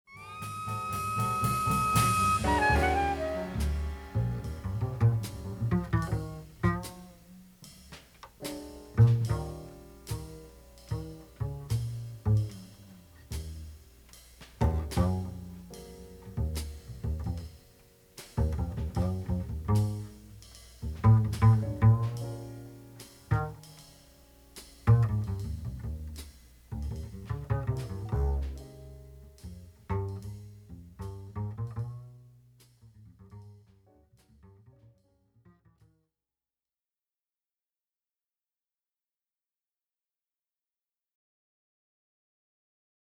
• Der Kontrabass (auch Bassgeige genannt) ist das grösste und tiefste Instrument der Streicher-Familie.
Tonbeispiel Kontrabass Jazz:
05-Kontrabass-Jazz.mp3